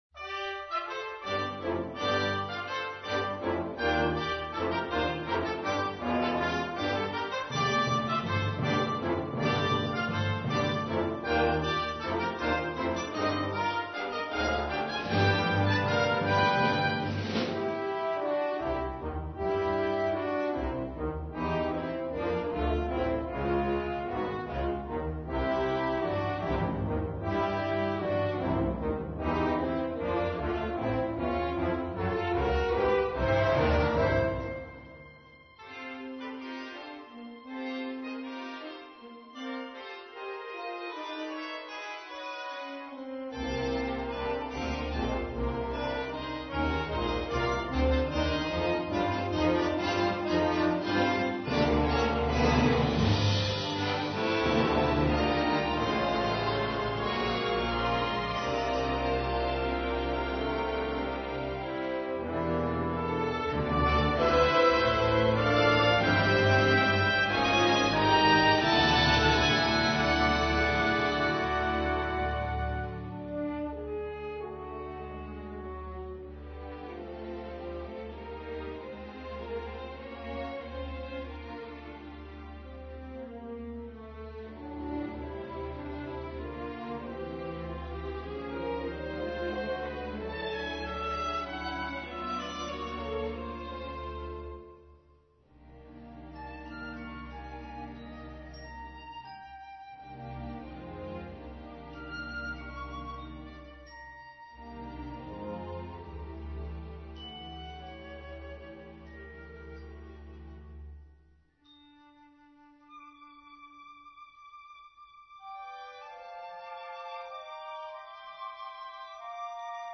Five Orchestral Works Inspired by New Mexico